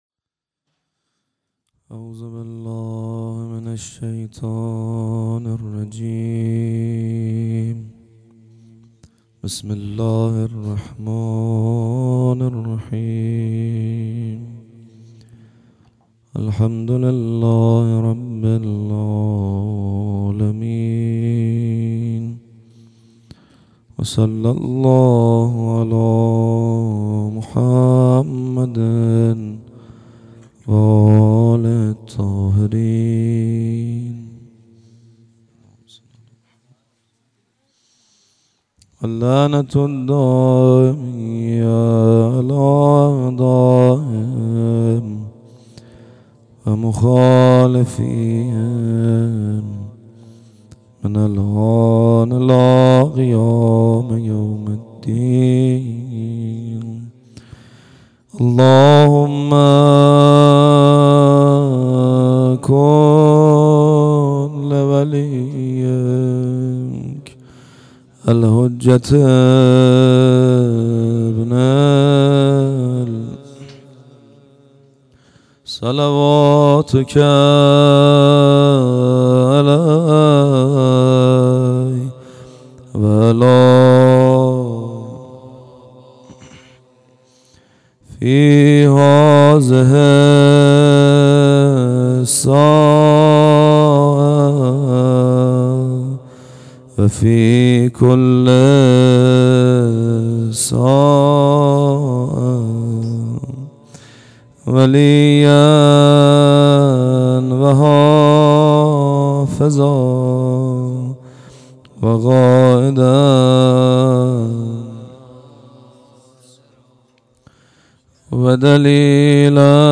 هيأت یاس علقمه سلام الله علیها
مدح